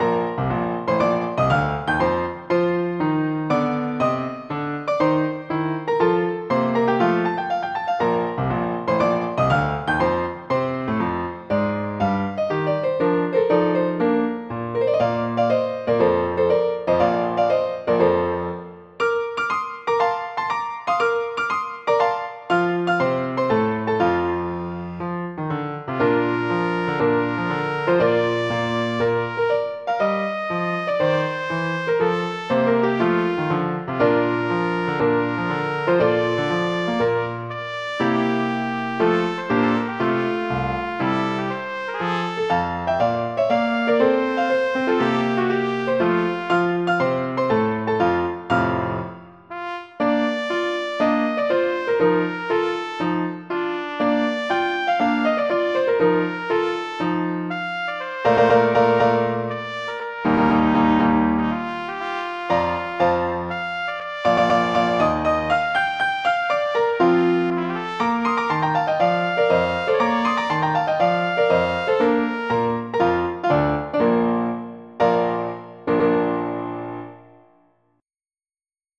Este foi produzido através do software de síntese MIDI TiMidity++.
Hymno Patriótico – apenas piano (Áudio .ogg) O seu browser não suporta este áudio. Hymno Patriótico – piano e trompete (Áudio .ogg) O seu browser não suporta este áudio.
hymno_patriotico_trumpet.ogg